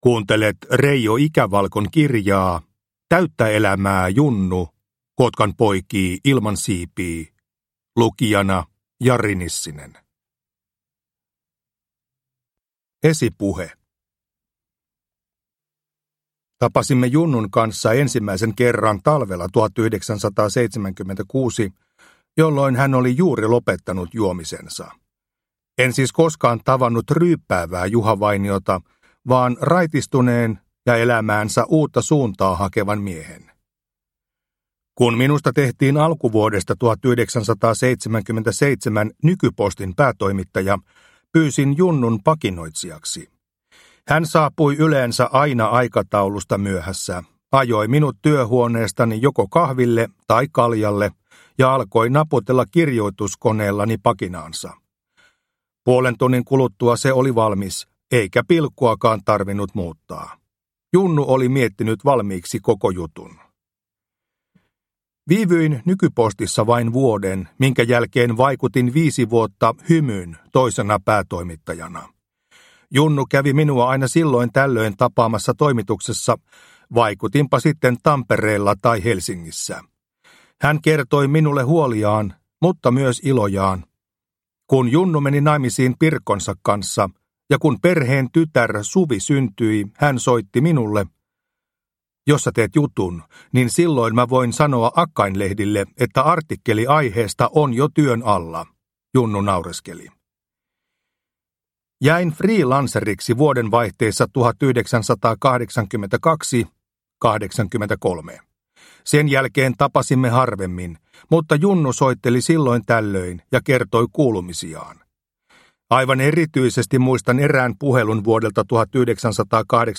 Täyttä elämää Junnu – Ljudbok – Laddas ner